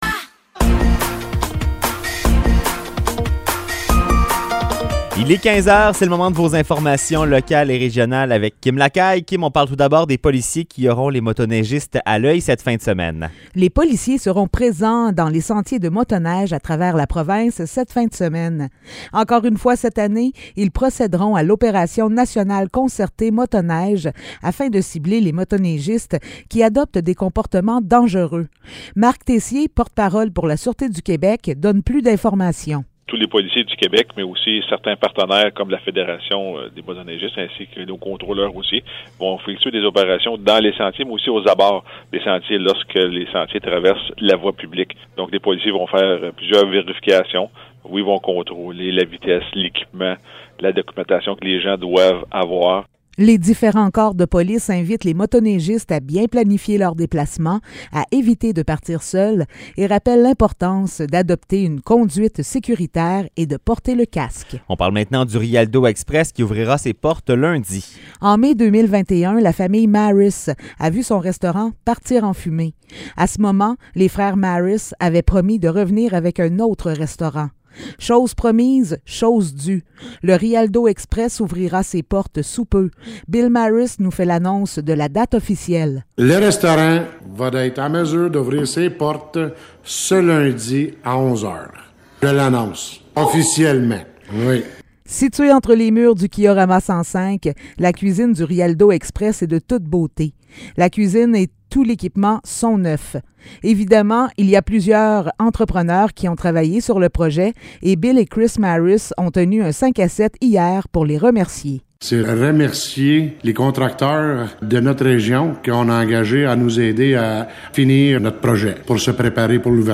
Nouvelles locales - 27 janvier 2023 - 15 h